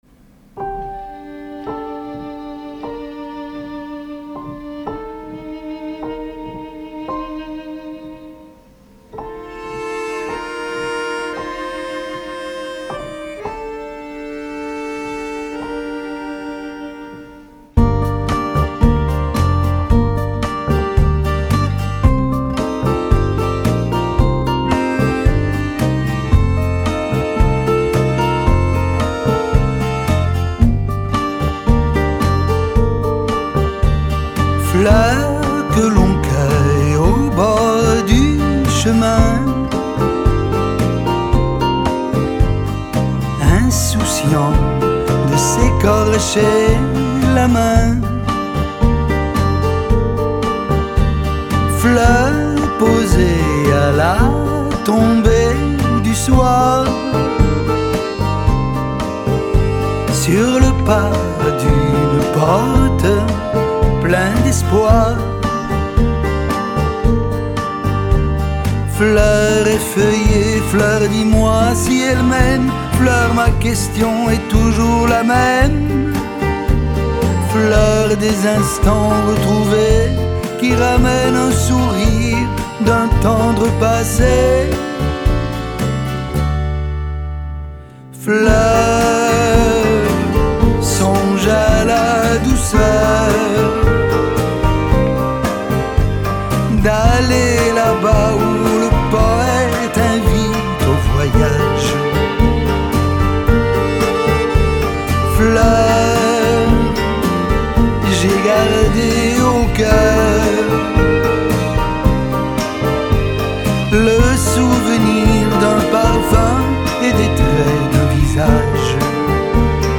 джаз, поп-музыка